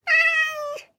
Minecraft Version Minecraft Version latest Latest Release | Latest Snapshot latest / assets / minecraft / sounds / mob / cat / stray / idle2.ogg Compare With Compare With Latest Release | Latest Snapshot